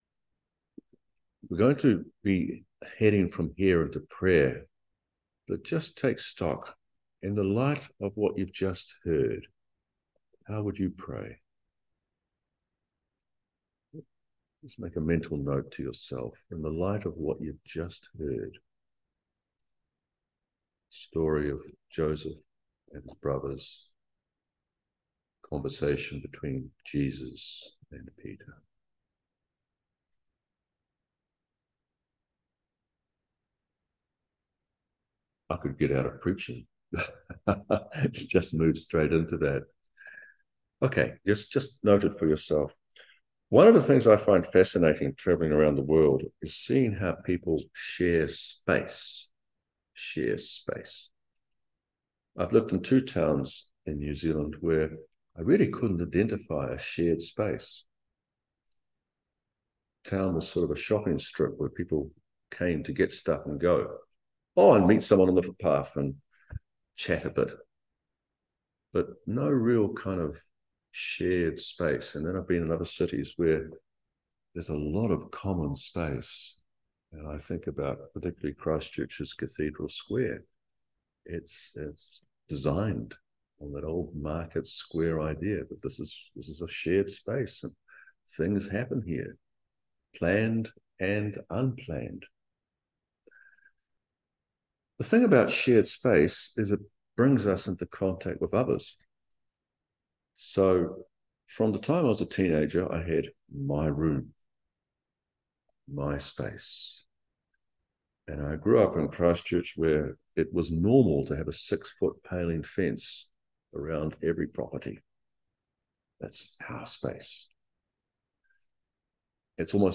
Matthew 18:21-35 Service Type: Holy Communion Forgiveness and living as brothers and sisters in Christ.